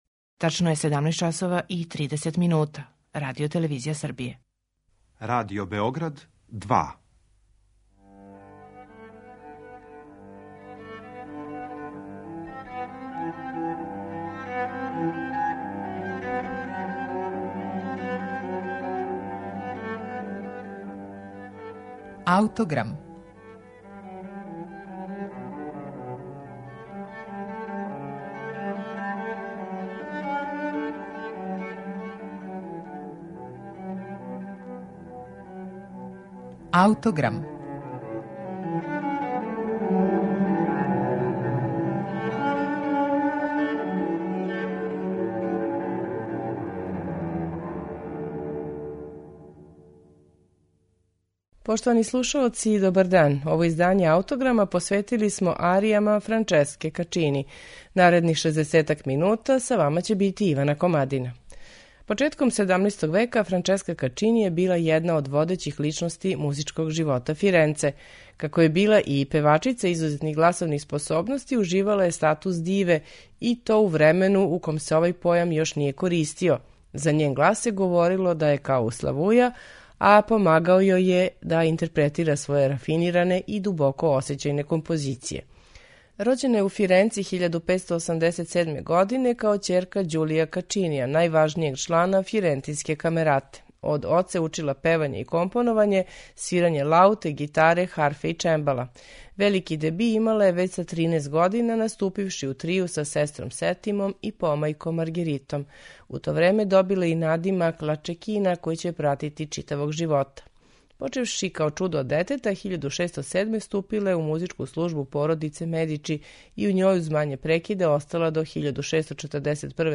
сопран
виолина
чембало
виола да гамба
гитара
лаута и удараљке
теорба, цитра и барокна гитара